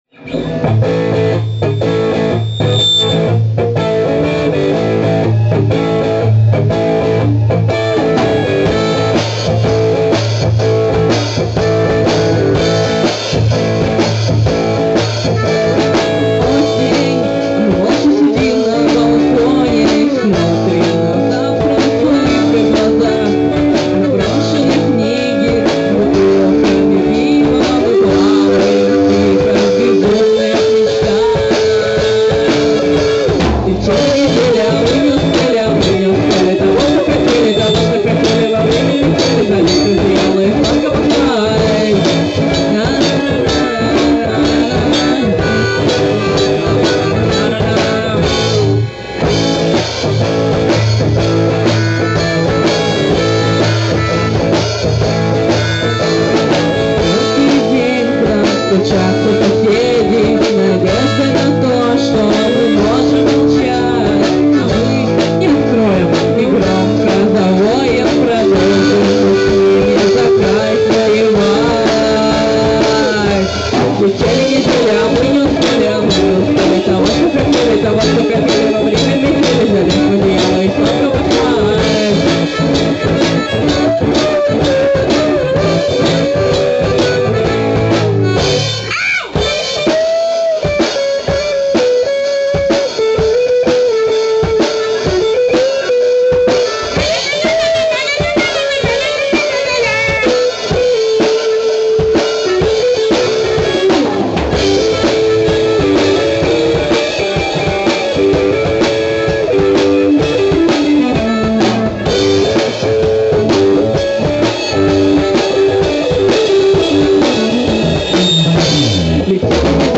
Live in Boom Studio
Акустическая версия композиции